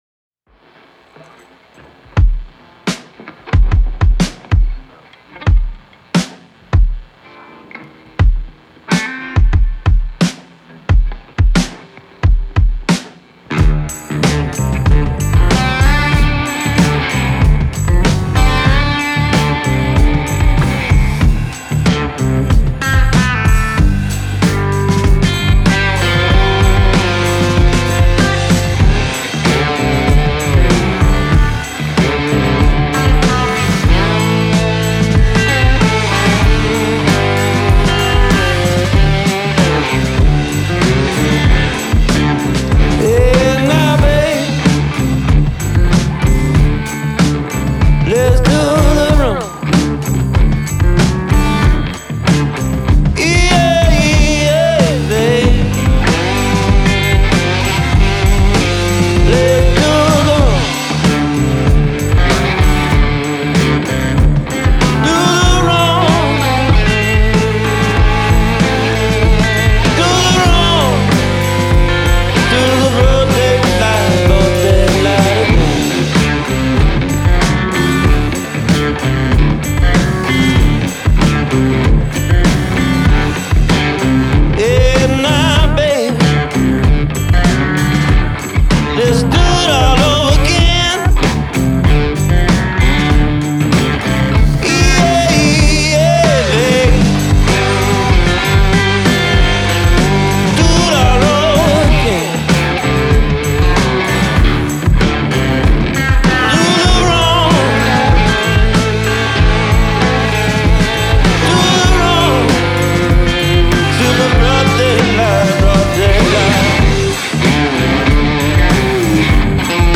the pair have been prolific with their blues rock